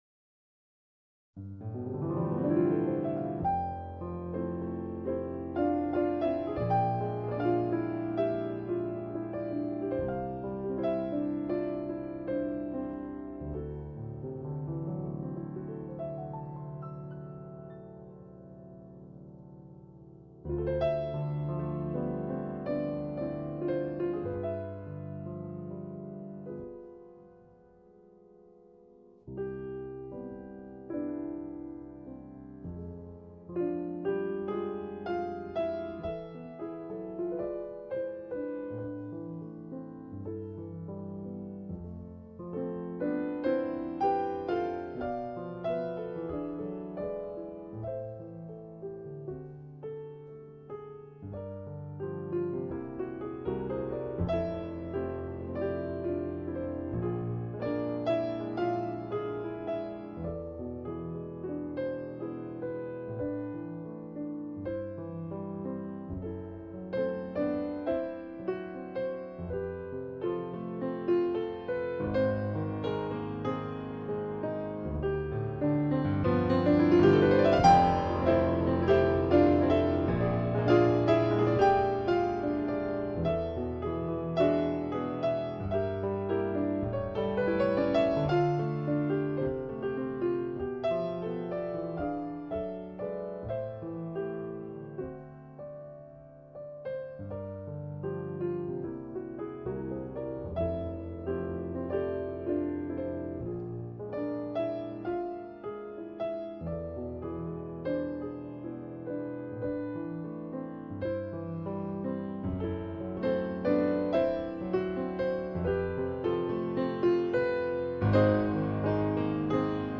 เปียโน